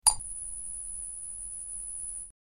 Neon Light Buzz Sound Effect
Description: Neon light buzz sound effect. Add an electric, atmospheric vibe to your project. Perfect for videos, games, or ambient city scenes, it captures the subtle hum and flicker of glowing lights.
Neon-light-buzz-sound-effect.mp3